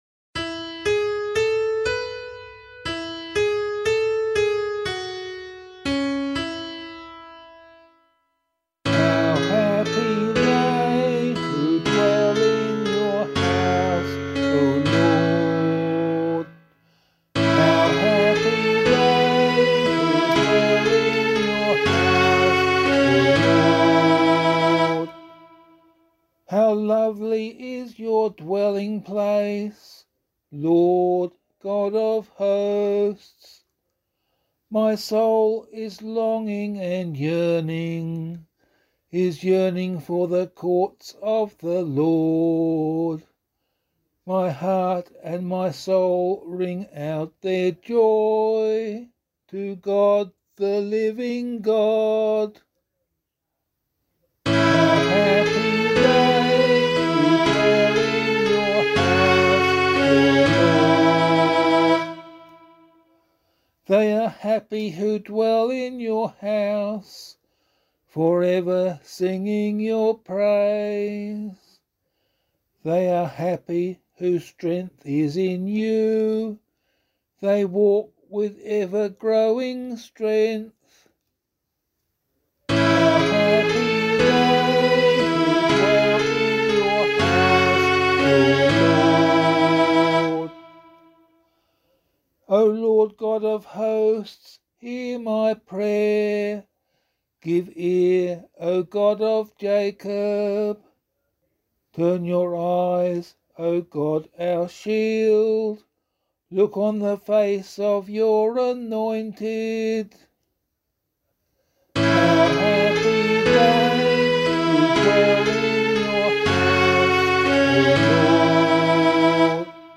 007 Holy Family Psalm C [LiturgyShare 8 - Oz] - vocal.mp3